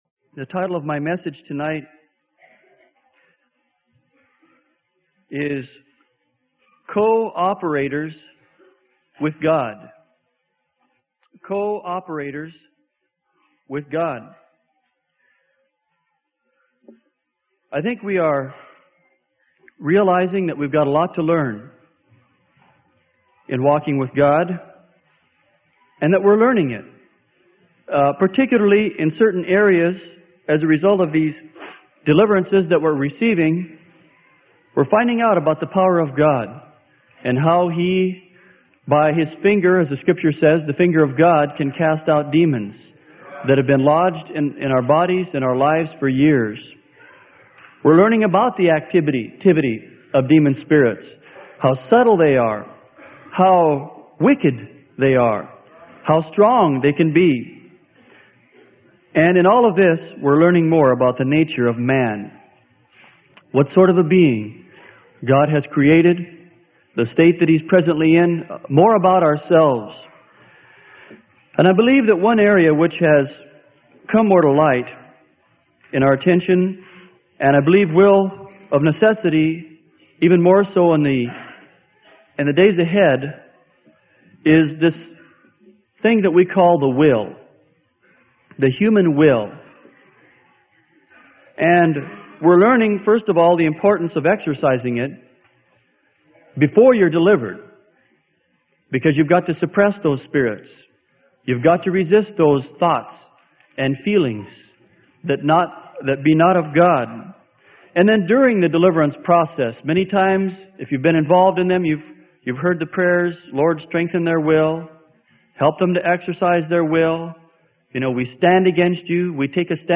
Sermon: Co-operating With God - Freely Given Online Library